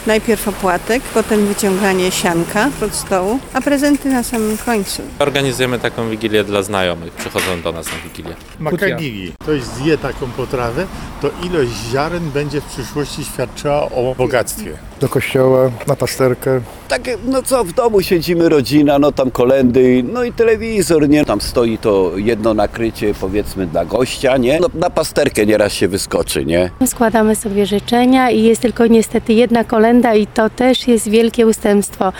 Wigilijne zwyczaje [SONDA]
Zielonogórzanie opowiadają nam o swoich wigilijnych tradycjach i zwyczajach.